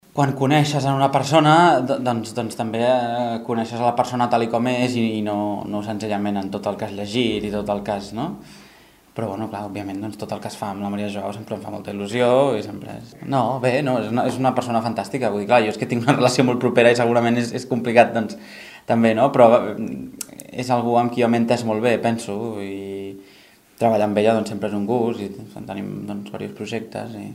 Abans de començar el concert va concedir una breu entrevista a Ràdio Capital